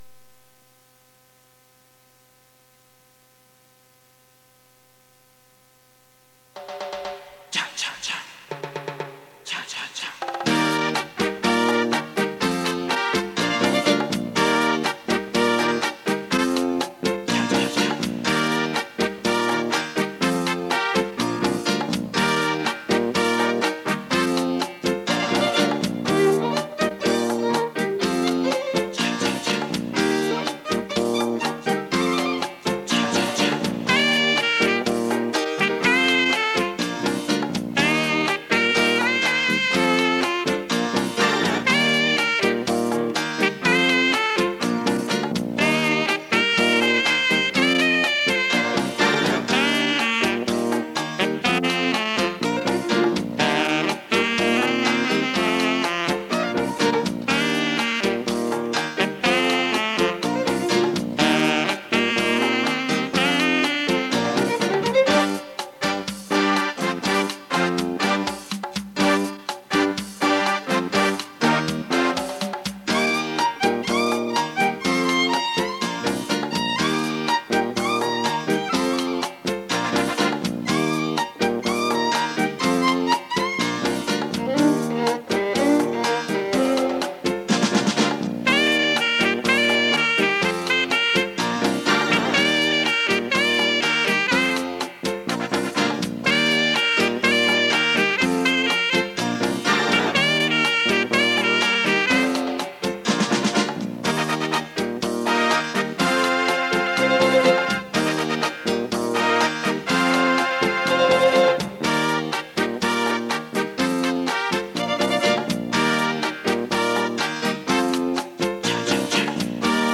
伦巴 牛仔舞 探戈 喳喳 三步 四步